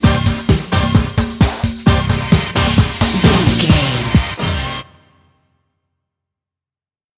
Fast paced
Ionian/Major
D
Fast
synthesiser
drum machine
Eurodance